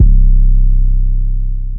808 1 {C} [ Rumble ].wav